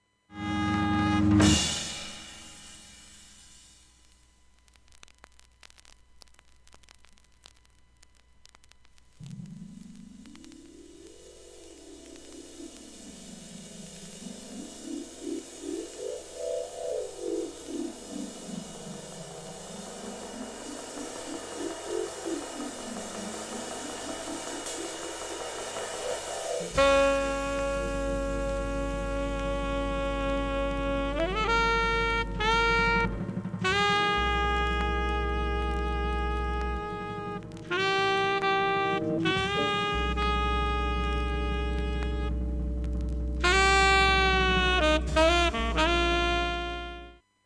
現物の試聴（上記）できます。音質目安にどうぞ ほか7回わずかプツ1箇所程度 音質良好全曲試聴済み。
MONO
マルチ・リード奏者